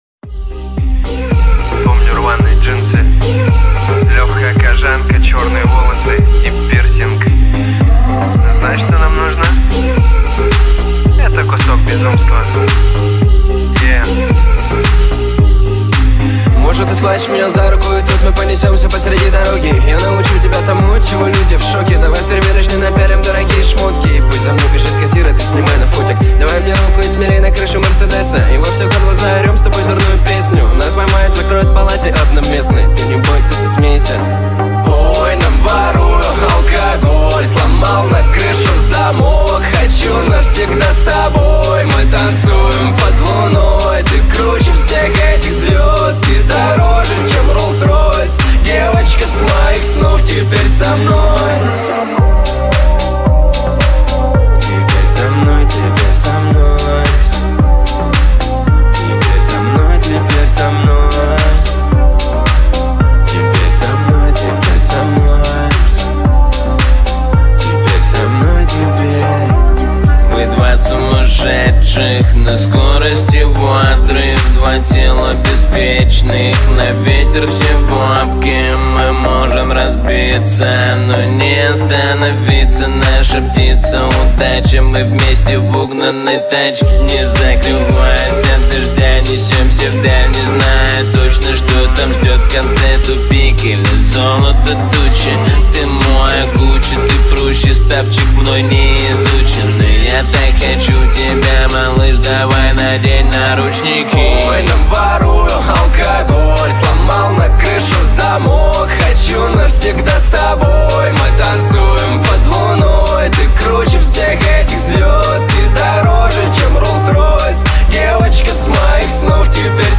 با ریتمی سریع شده